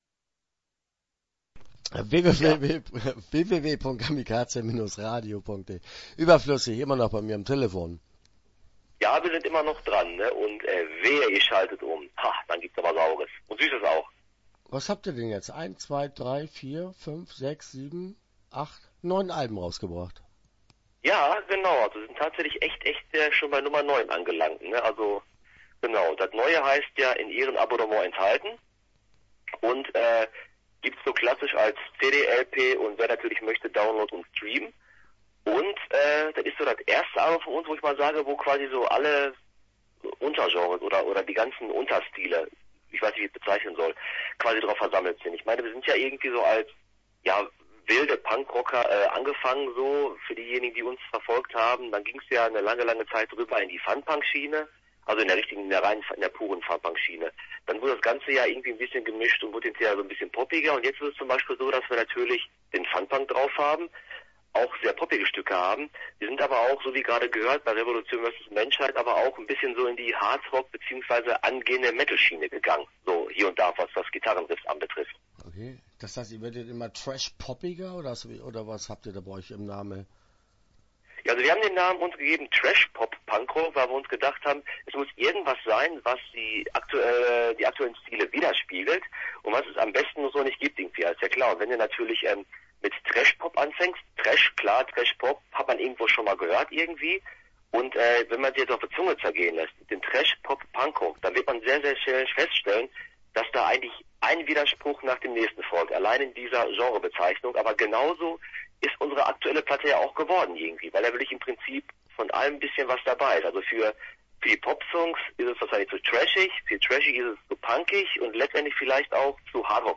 Überflüssig - Interview Teil 1 (13:55)